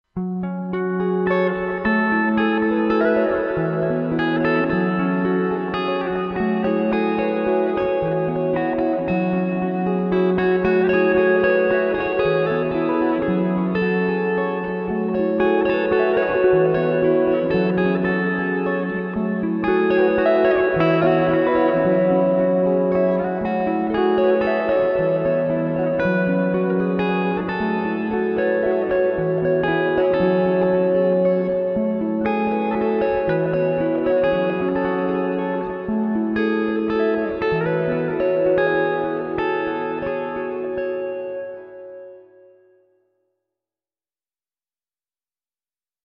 Спокойные И Тихие Рингтоны » # Рингтоны Без Слов